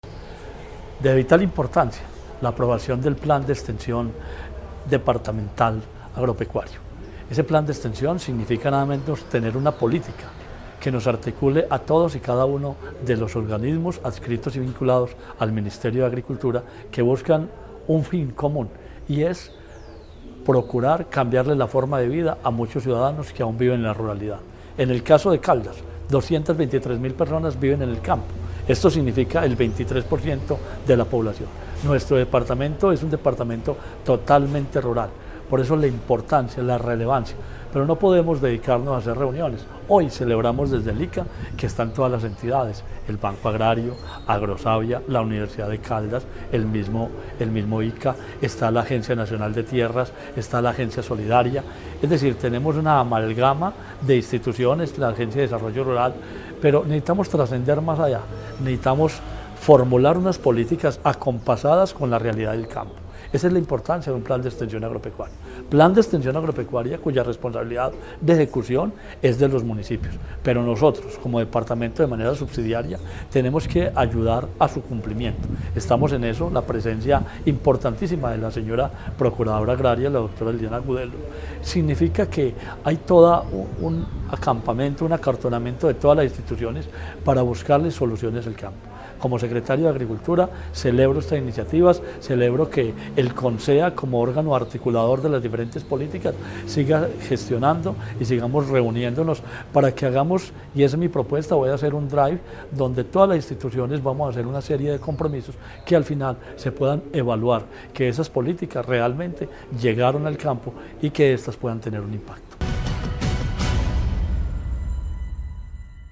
La Gobernación de Caldas, a través de su Secretaría de Agricultura y Desarrollo Rural de Caldas, llevó a cabo el tercer encuentro del año del Consejo Seccional de Desarrollo Agropecuario, Pesquero, Forestal, Comercial y de Desarrollo Rural (CONSEA) en el auditorio del Instituto Colombiano Agropecuario (ICA). En este espacio se socializó la ordenanza 1003, mediante la cual se adoptó el Plan Departamental de Extensión Agropecuaria (PDEA), una estrategia que busca fortalecer la ruralidad y abrir nuevas oportunidades de desarrollo para el campo caldense.
Marino Murillo Franco, secretario de Agricultura y Desarrollo Rural de Caldas